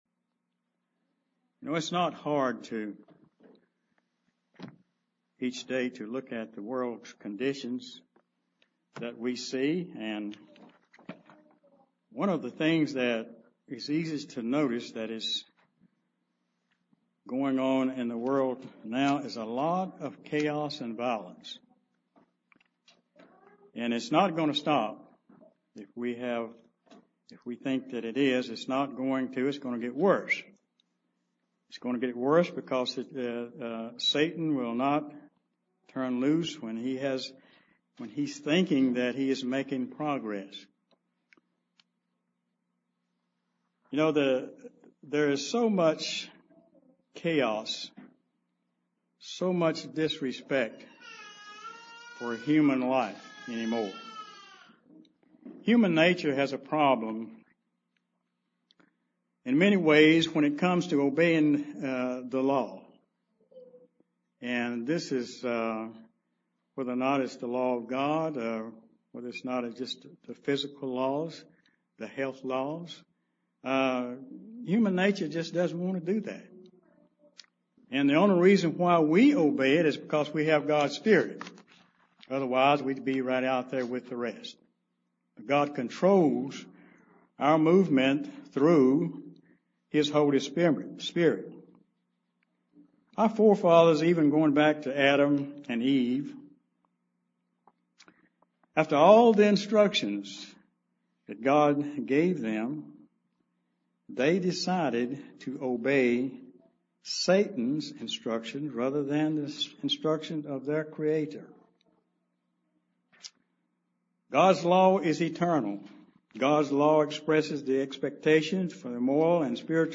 UCG Sermon Studying the bible?
Given in Charlotte, NC